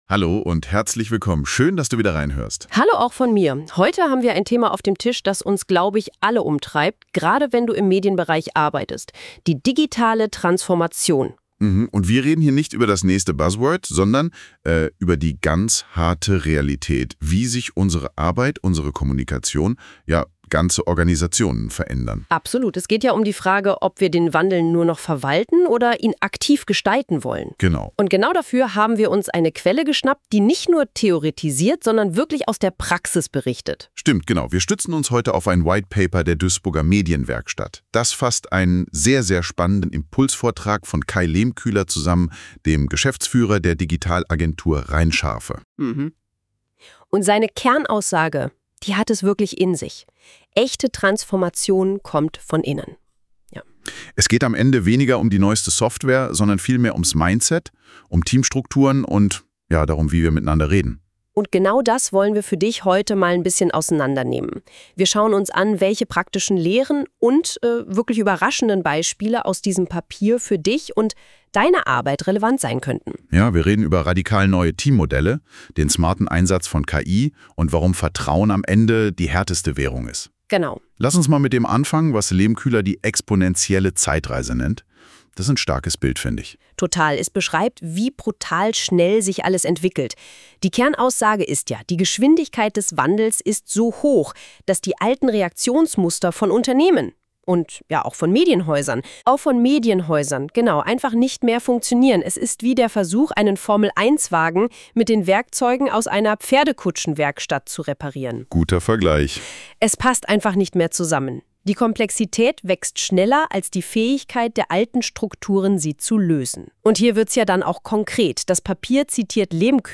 Unsere KI-Hosts diskutieren, wie Vertrauen, Storytelling und partizipative Prozesse den Unterschied machen.